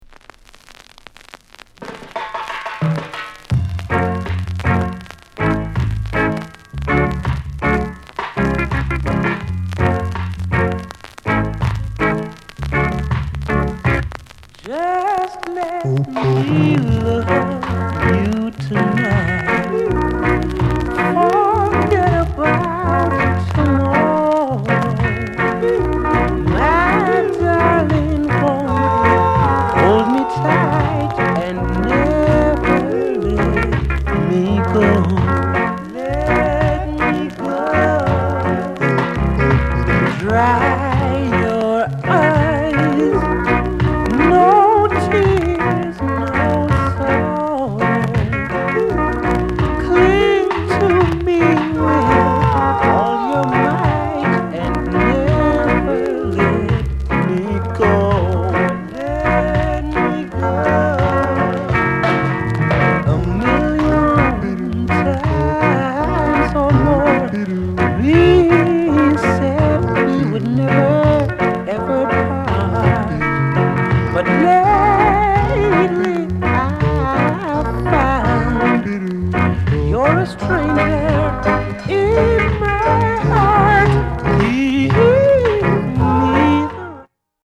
SOUND CONDITION A SIDE VG-